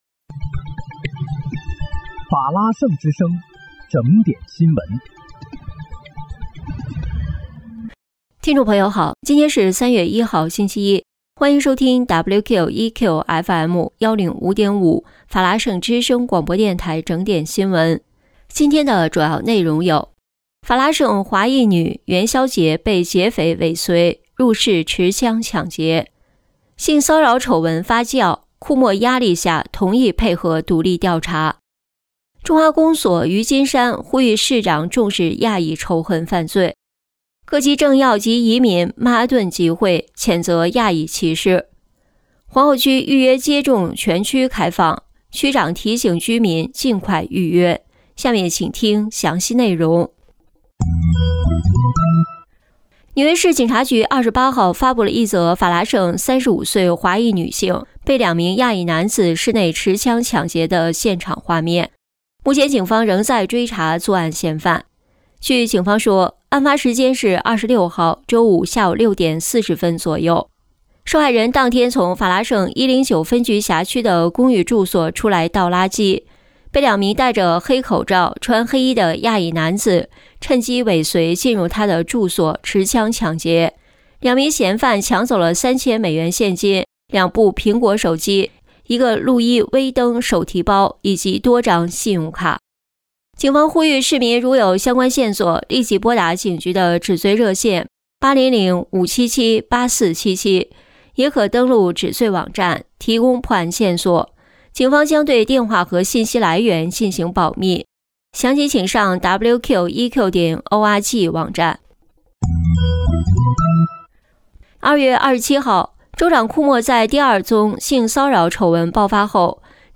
3月1日(星期一）纽约整点新闻